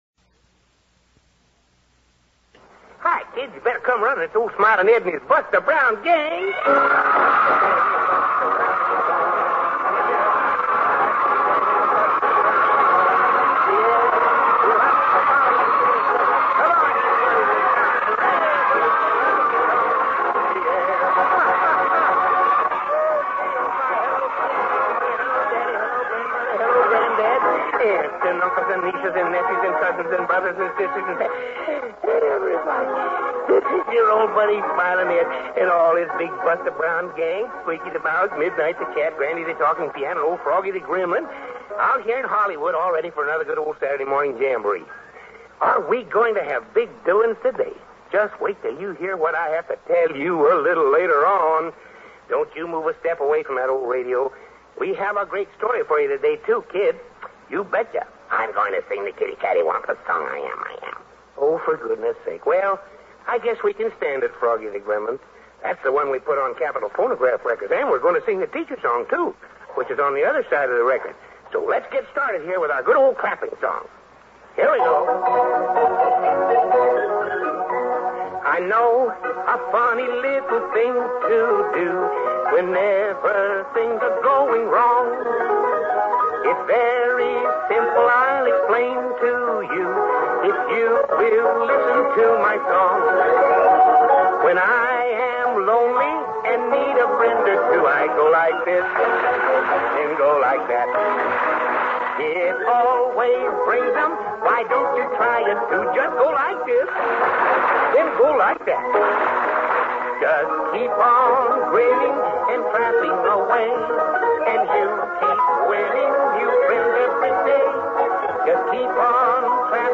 A Buster Brown radio series began in 1943 with Smilin' Ed McConnell on the West Coast NBC Radio Network.